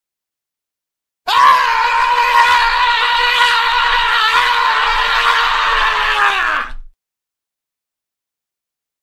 دانلود صدای داد و فریاد مرد 2 از ساعد نیوز با لینک مستقیم و کیفیت بالا
جلوه های صوتی